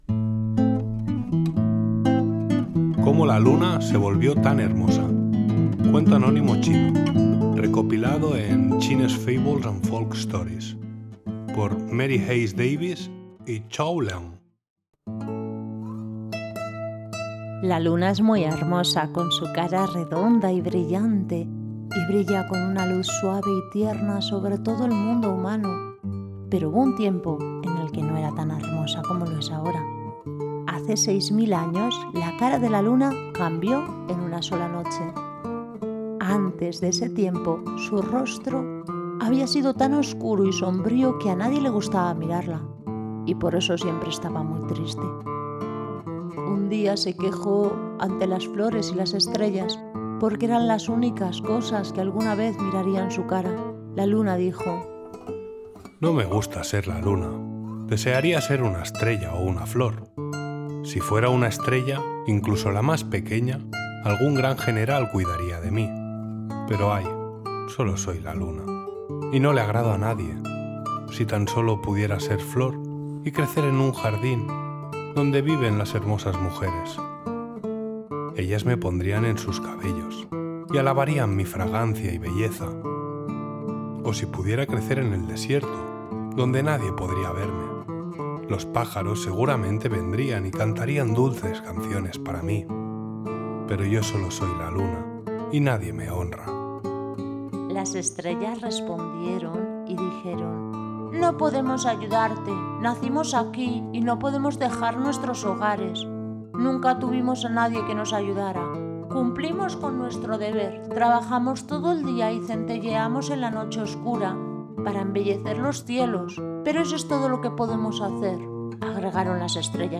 Cuento narrado: Cómo la Luna se volvió hermosa
cuento-chino-como-la-luna-se-volvio-tan-hermosa.mp3